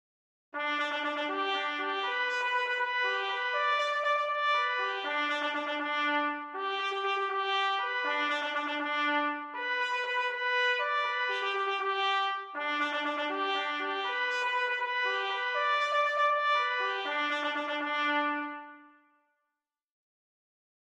Traditional : Stable Call - a military bugle call used by the Cavalry: Sheet Music
Traditional Bugle Call : Stable Call